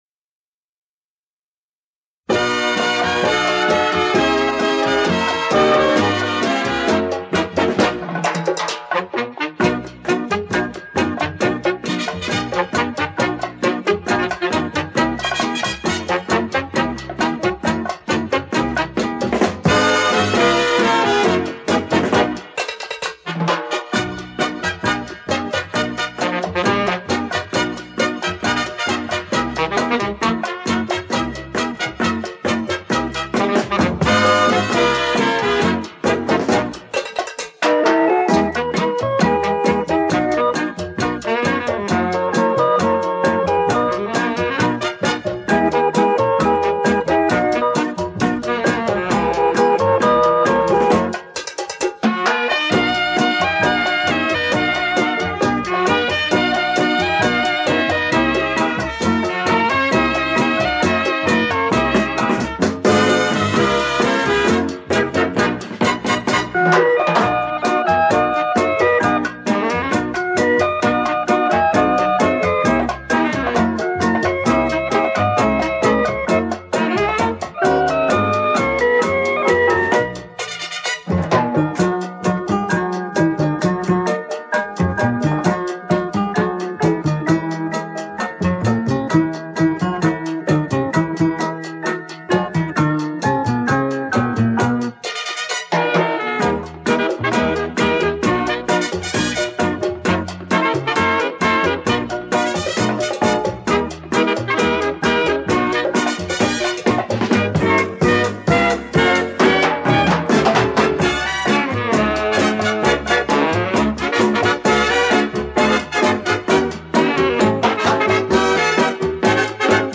恰恰舞